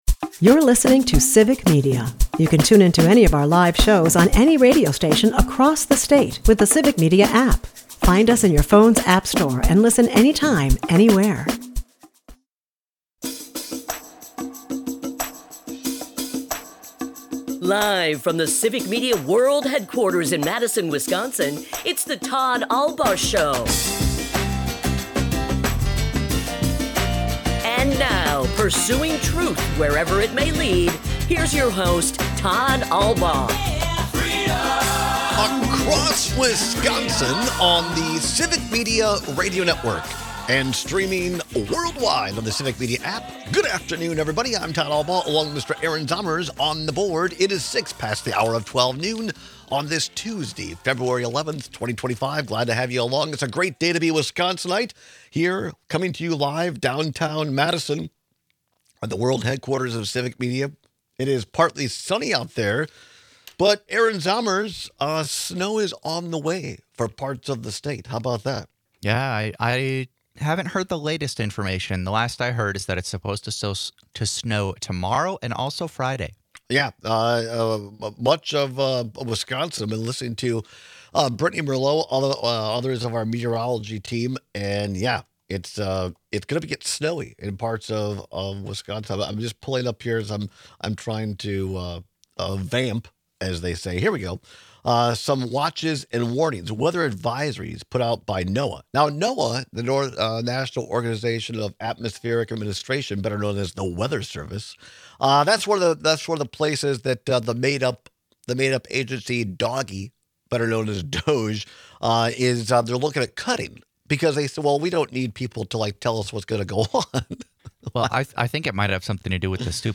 Broadcasts live 12 - 2p across Wisconsin.